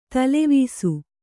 ♪ talevīsu